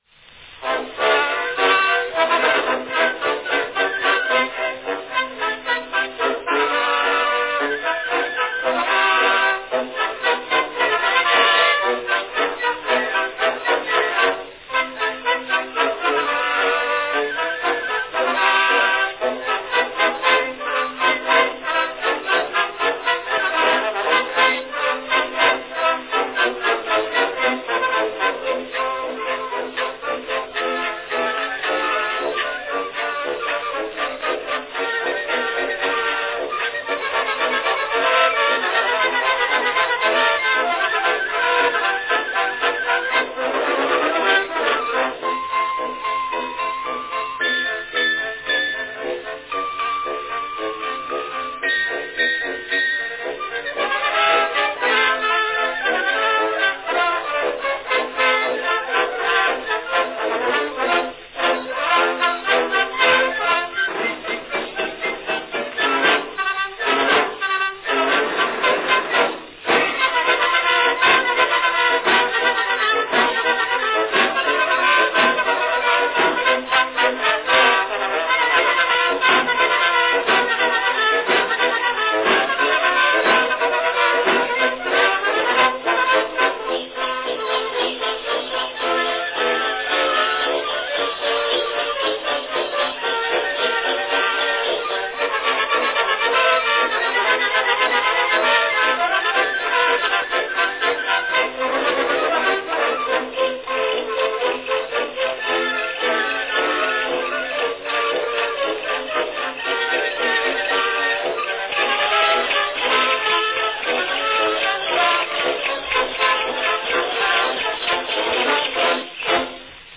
A catchy ragtime piece from 1909, the Yankiana Rag, by Edison's American Symphony Orchestra.
Category Orchestra
Performed by American Symphony Orchestra
Announcement None
This ragtime number, written in 1908, was a musical highlight and featured in the relatively short five-month New York run of "Miss Innocence".
It cannot fail to please all lovers of light musical compositions when rendered by orchestra.